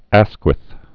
(ăskwĭth), Herbert Henry First Earl of Oxford and Asquith. 1852-1928.